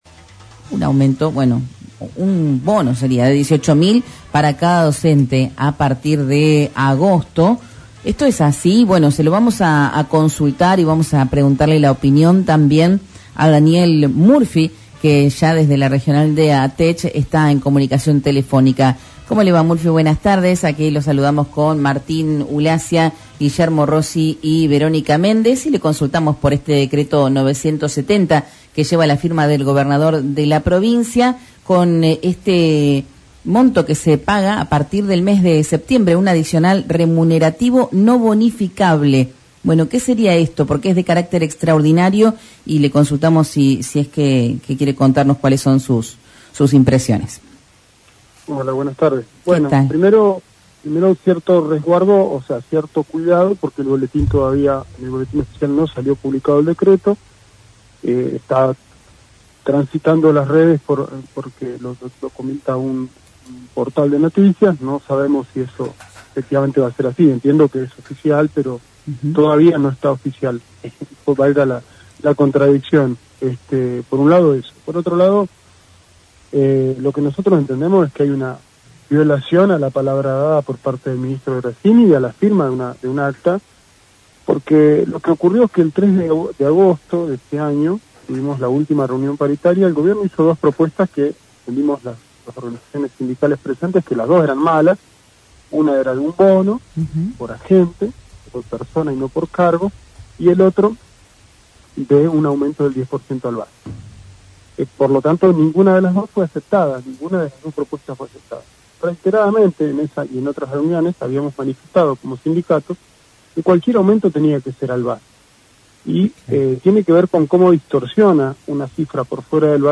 En diálogo con LaCienPuntoUno